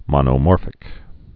(mŏnō-môrfĭk) also mon·o·mor·phous (-fəs)